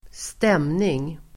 Uttal: [²st'em:ning]